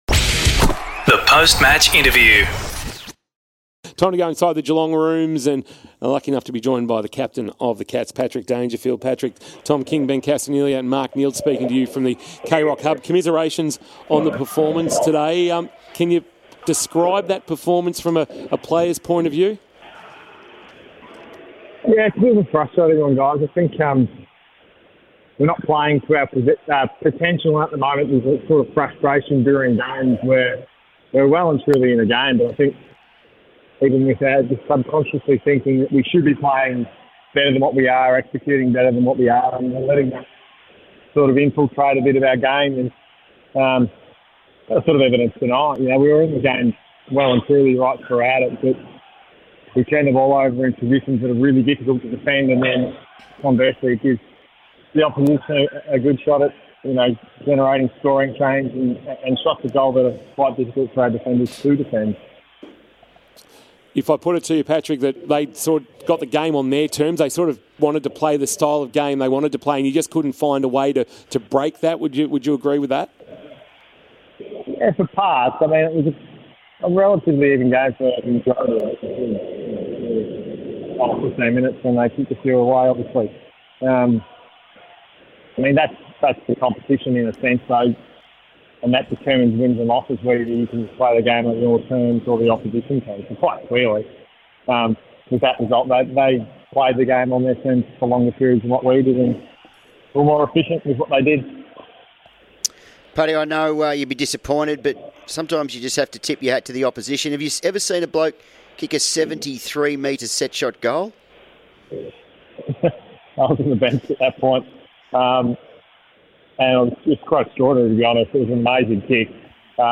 2023 - AFL ROUND 3 - GOLD COAST vs. GEELONG: Post-match Interview - Patrick Dangerfield (Geelong Cats)